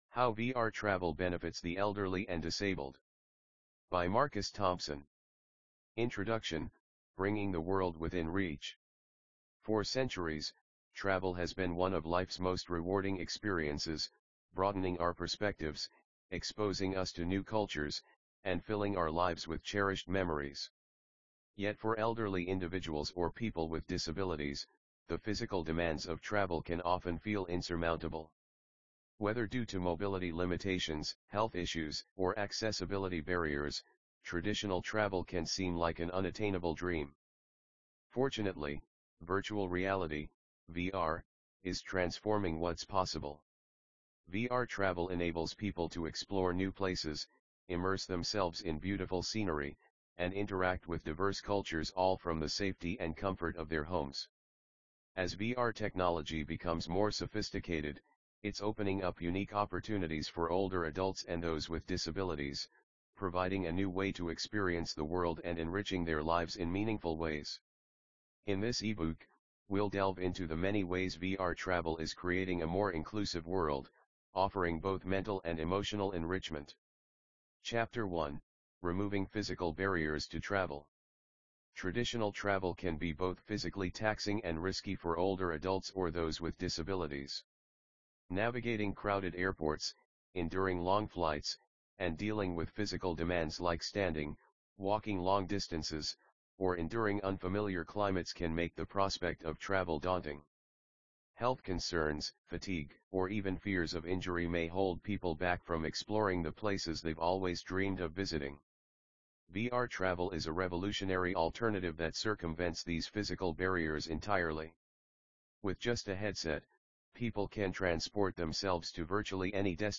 🎧 FREE AUDIOBOOK
Audio narration of WAVES
vr-elderly-audiobook.mp3